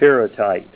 Help on Name Pronunciation: Name Pronunciation: Pyrrhotite + Pronunciation
Say PYRRHOTITE Help on Synonym: Synonym: Magnetic pyrites